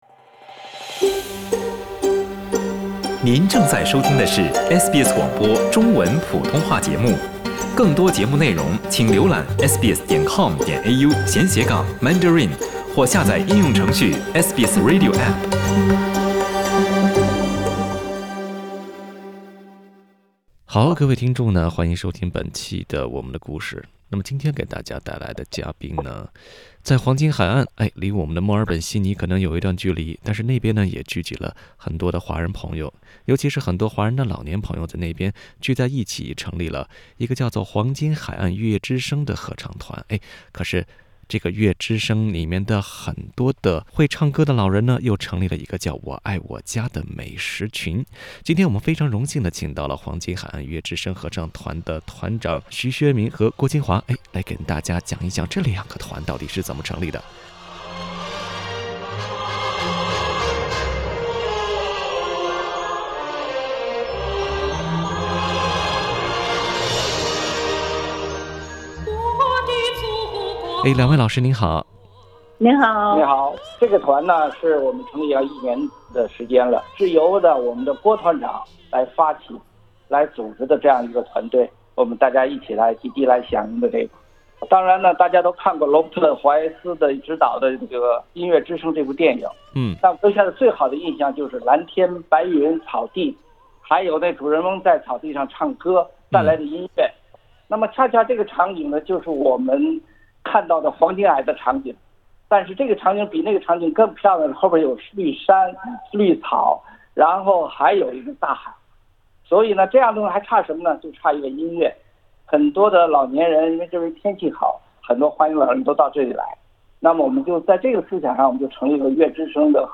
收听采访， 请点击图片音频 ，寻找“家的感觉“。